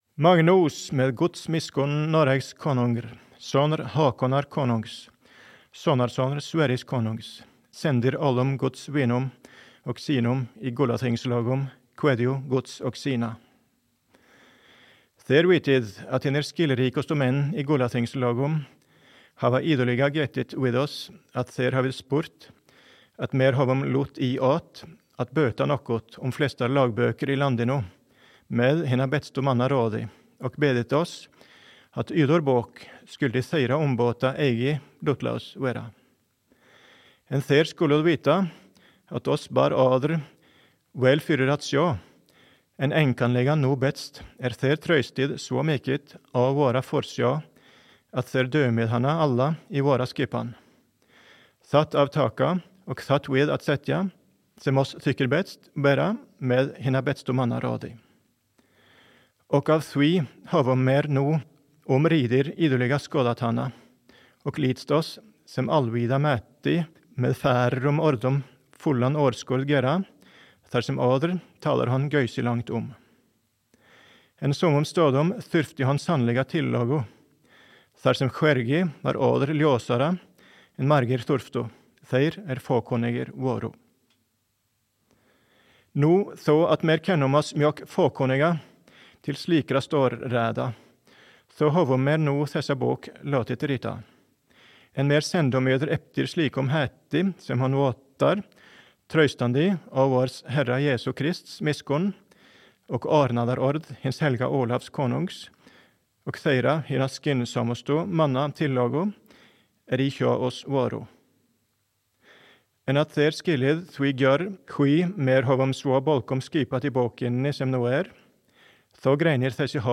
Opplesning av Landslovens prolog på gammelnorsk.